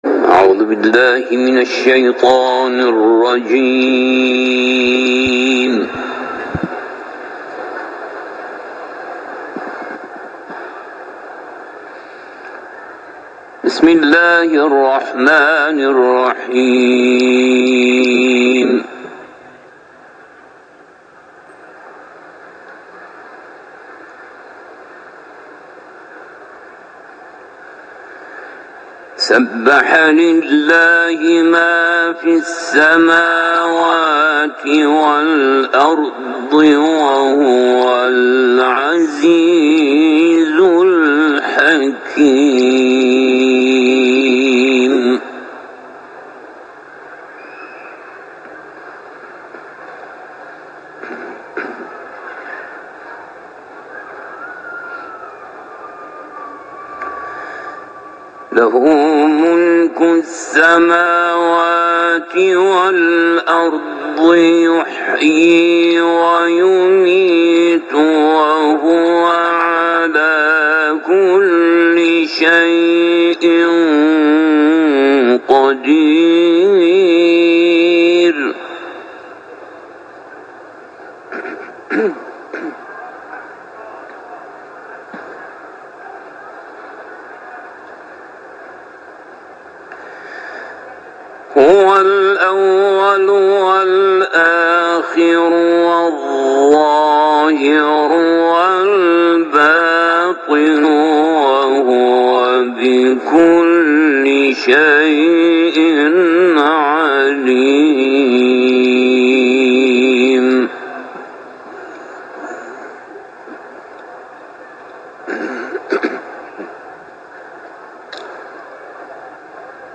قاری بین‌المللی قرآن در حرم مطهر رضوی به تلاوت آیات یک تا 10 سوره «حدید» پرداخت.
تلاوت ، سوره حدید